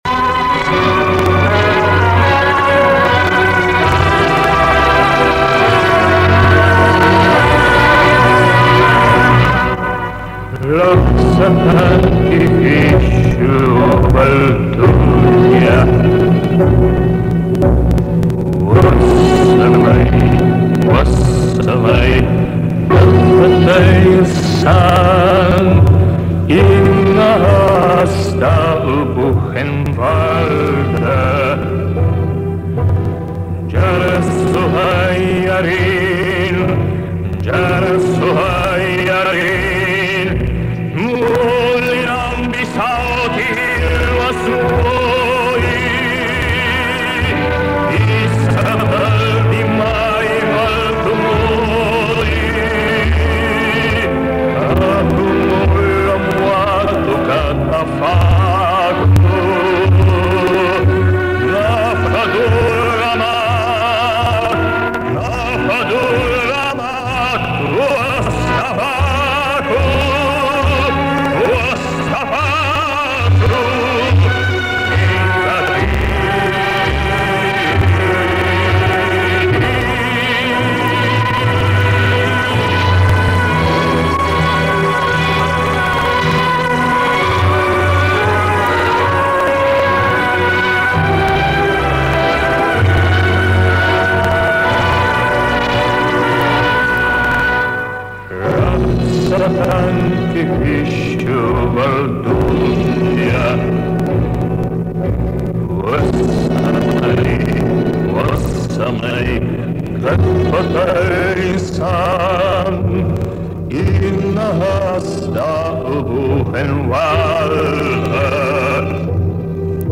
Запись основательно покорежена.